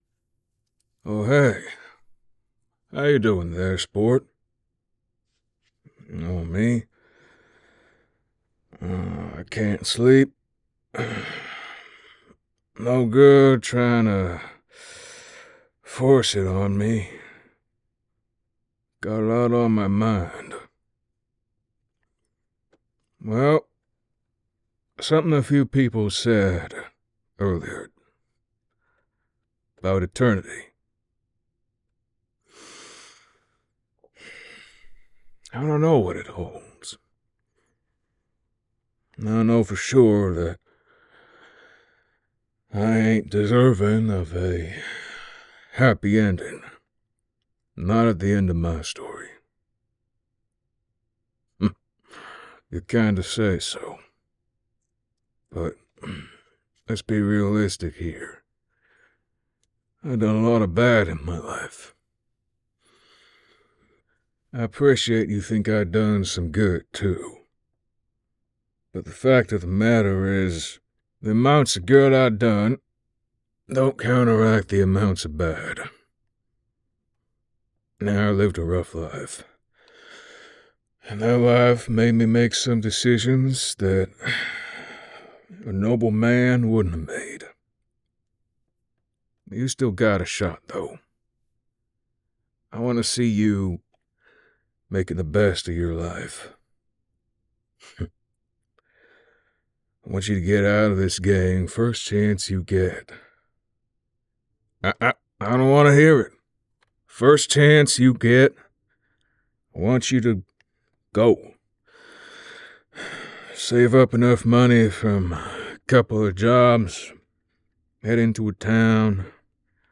A nice little affirmation ASMR, with many people's favorite cowboy, Arthur Morgan. Outlaw, scoundrel, and devil depending on who you ask, he shares his campfire with you after getting you out of a sticky situation. You've known each other for a while and he's damn proud of you, and believes you can achieve truly great things.